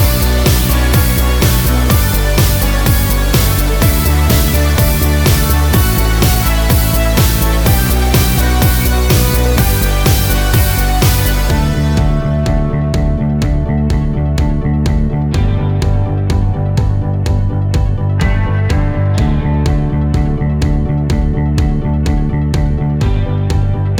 no Backing Vocals Dance 3:41 Buy £1.50